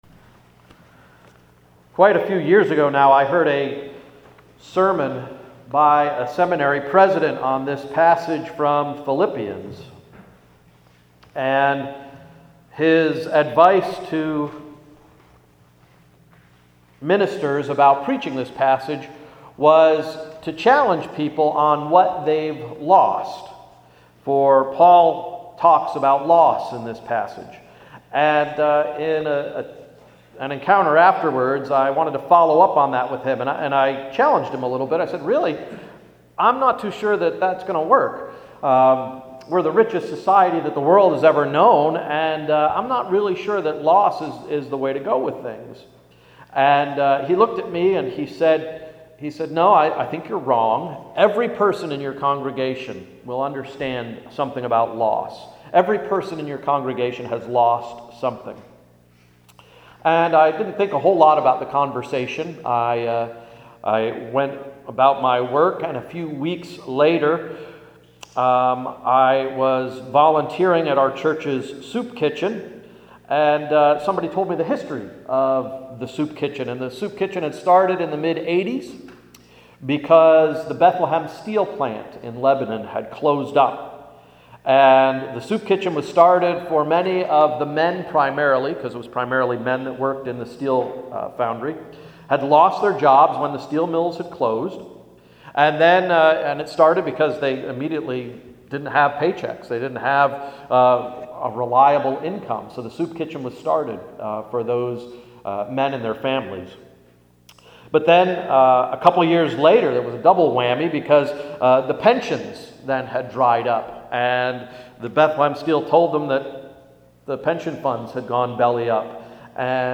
Sermon of October 2, 2011–“What Have You Lost?”